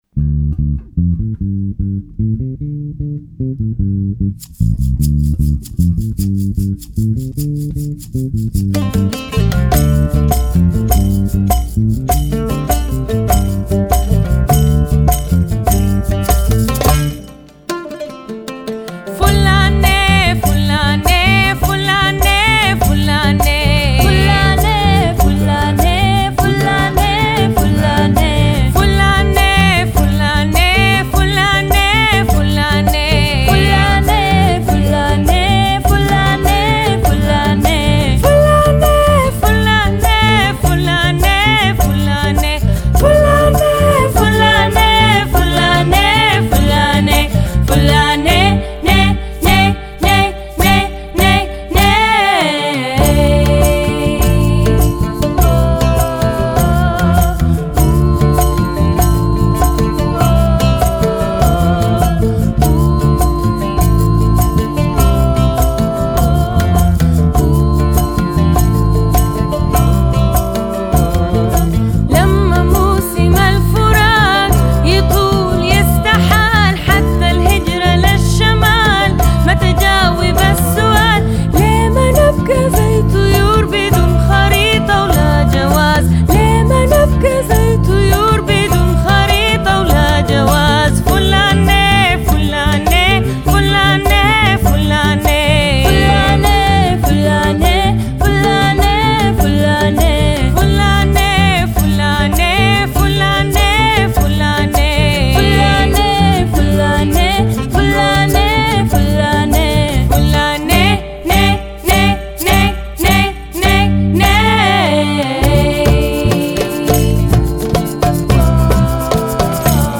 Epoque :  Musique d'aujourd'hui
Style :  Avec accompagnement
Effectif :  UnissonVoix égales
Enregistrement Tutti